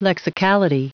Prononciation du mot lexicality en anglais (fichier audio)
Prononciation du mot : lexicality